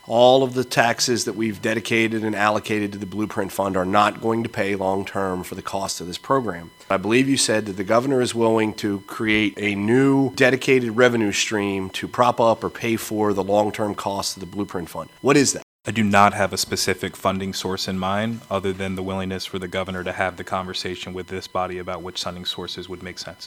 A joint hearing this week on Governor Moore’s plans to make adjustments to the Blueprint for Education made it clear that help is needed for continued funding.  Delegate Jason Buckel discussed with Moore’s Chief of Staff Fagan Harris what the administrations wants to do…